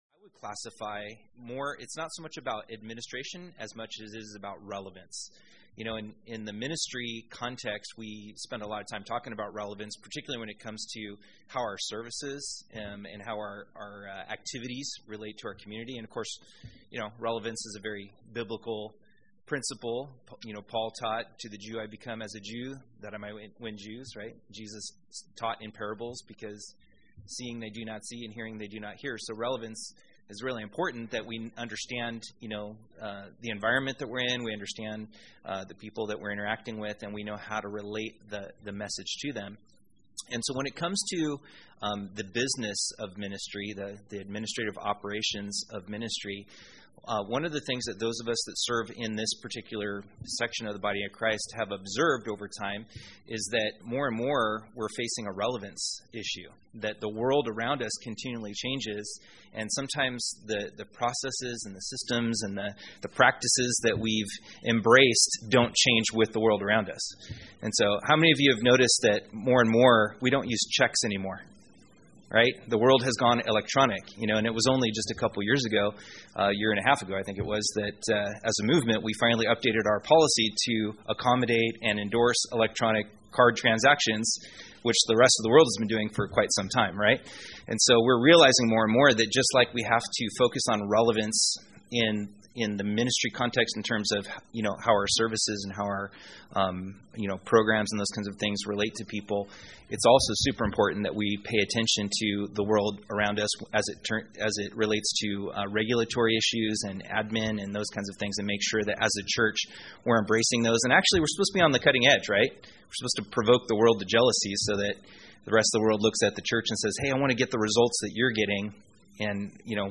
Workshop: What you don’t know could hurt you